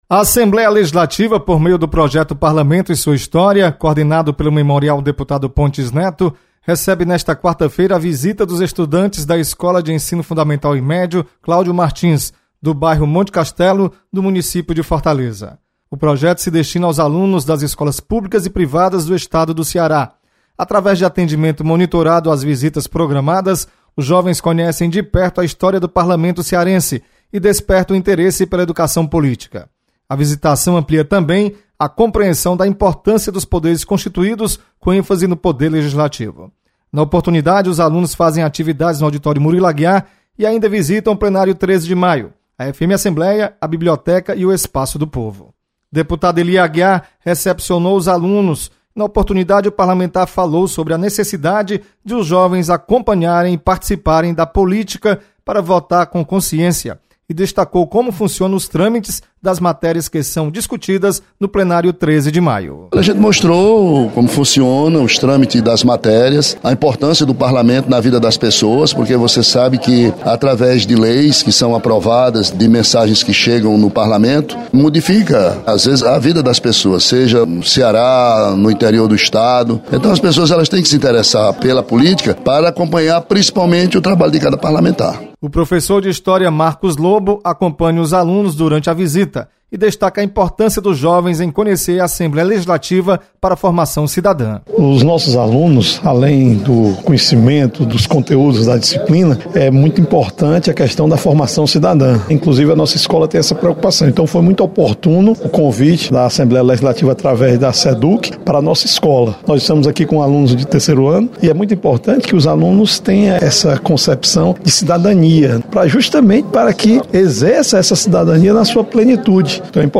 Você está aqui: Início Comunicação Rádio FM Assembleia Notícias Memorial